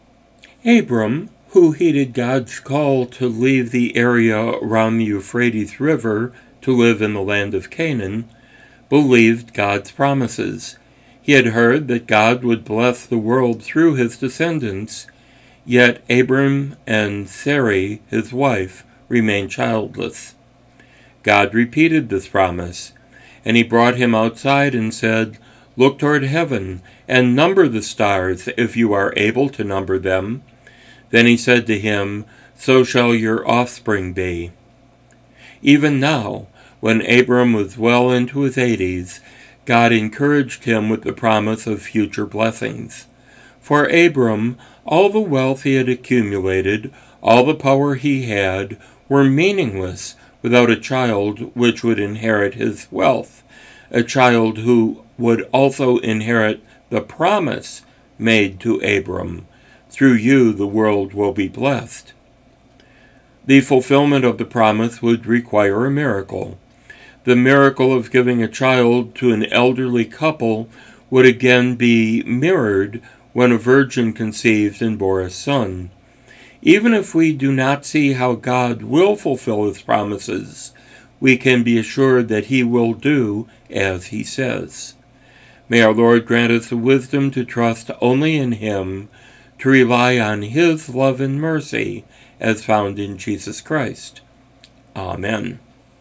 Daily Moments of Meditation devotions as heard each weekday on KJOE FM 106.1 in Slayton.